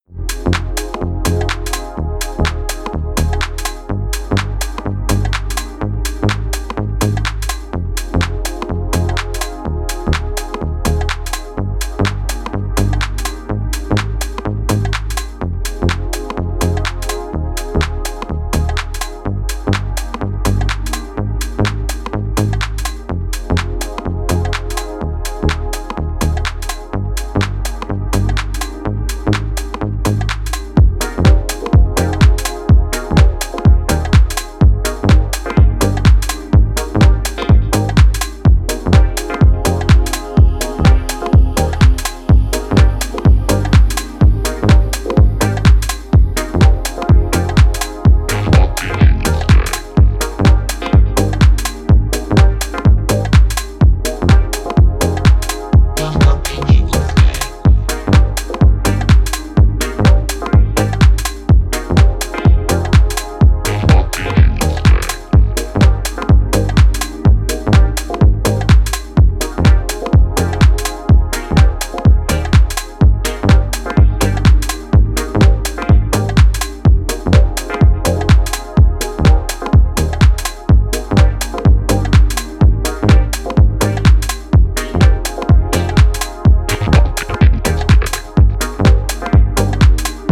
House basslines with a bit of deeper techno in between.